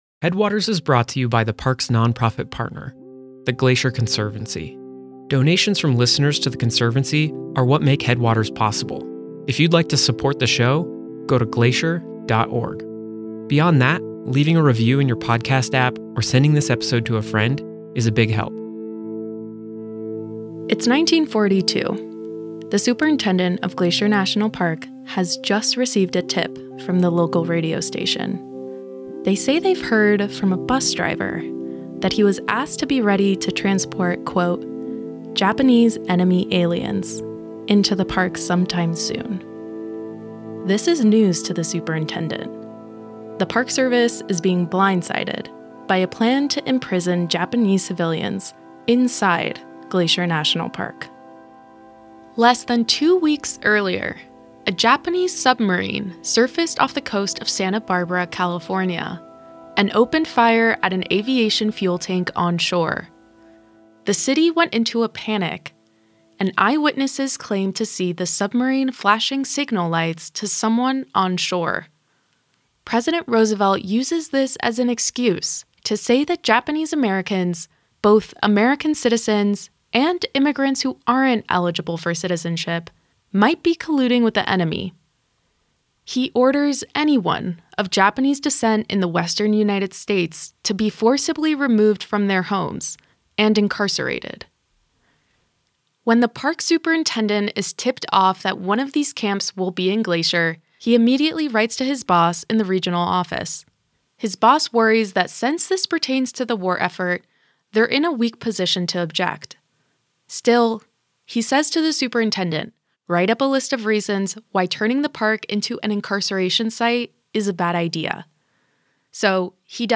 [ethereal chords start]